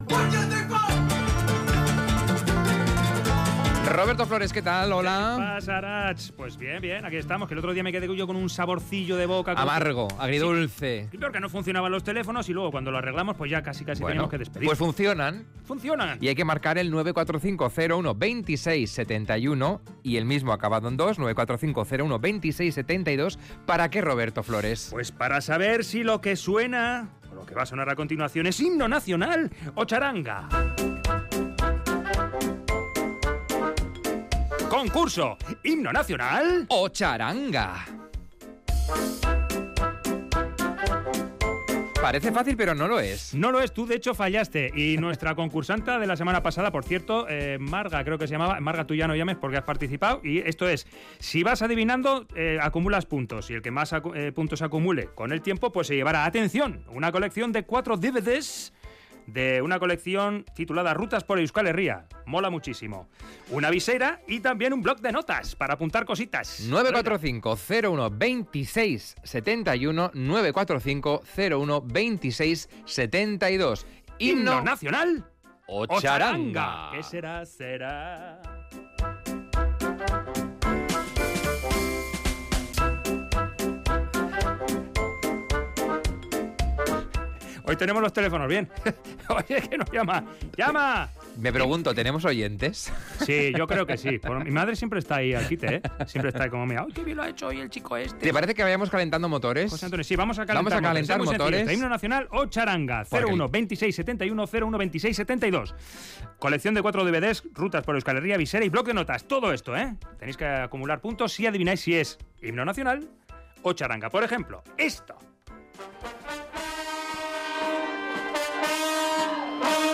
Audio: ¿Lo que oyes es un himno nacional o la música de una txaranga? Aunque pueda parecer lo contrario no es tan fácil distinguirlos.